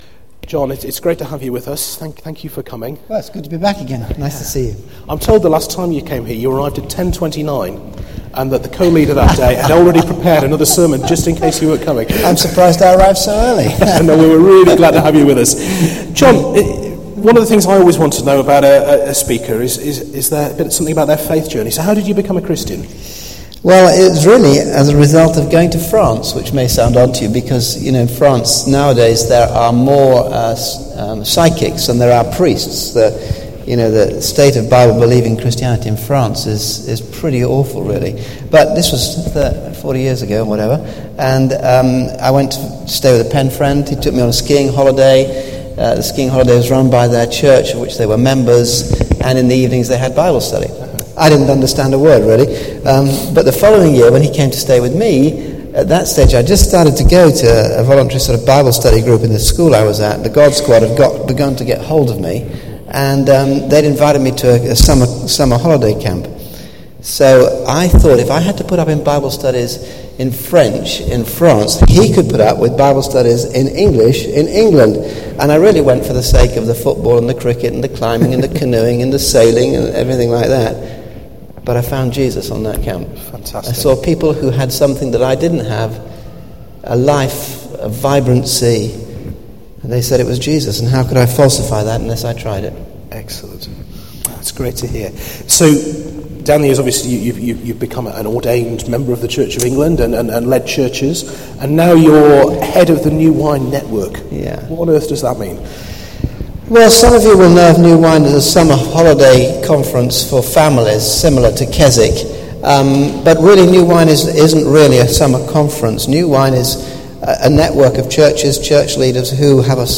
Centenary 2011 sermons – Ephesians 3v14 to 21